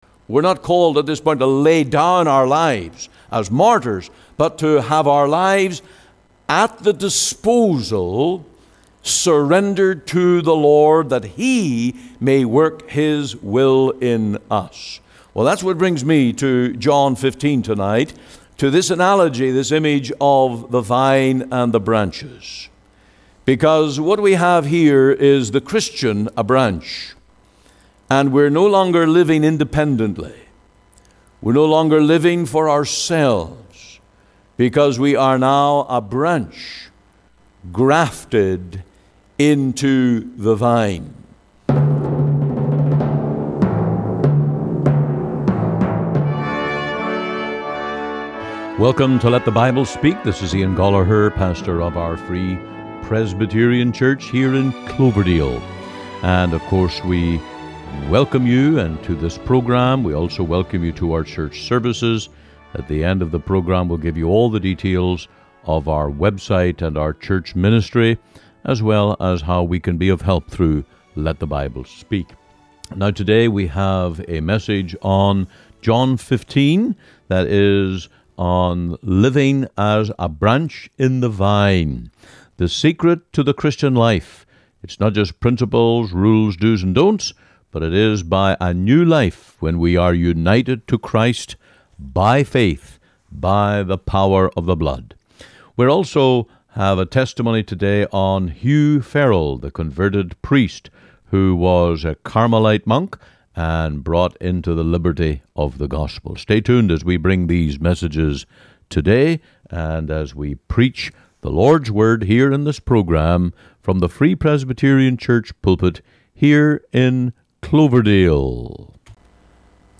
Sermons | The Free Presbyterian Church in Cloverdale